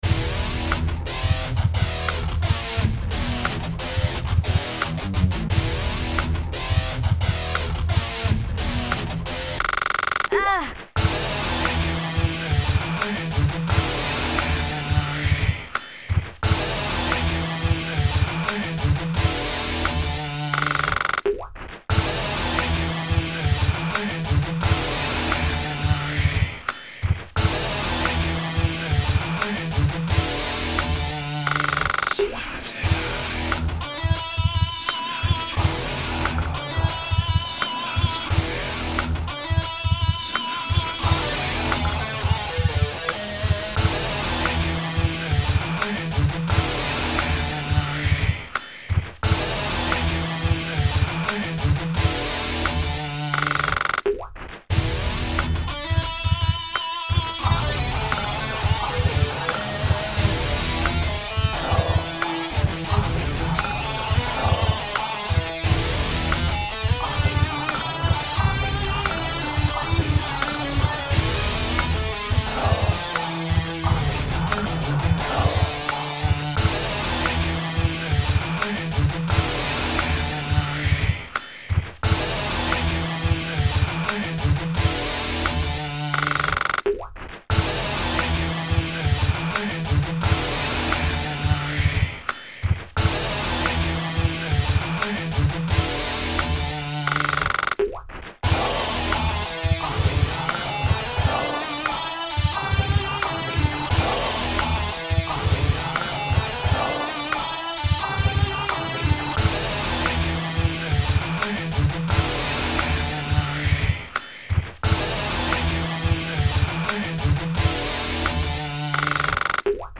But, at least this one has lots of chicken noises.